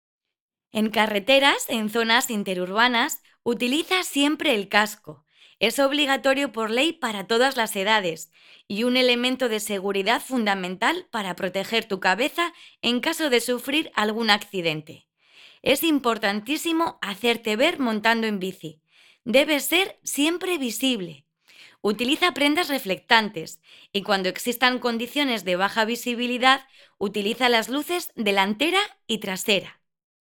THE STUDIO SERVICES MUSIC ARTIST MEDIA CONTACT THE STUDIO SERVICES MUSIC ARTIST MEDIA CONTACT HELMET DOMENTARY STORYTELLER 1. Voz en Off Documentales